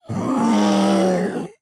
evil-turkey-v3.ogg